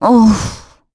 Valance-Vox-Deny_kr.wav